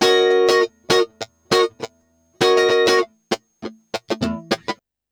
100FUNKY06-R.wav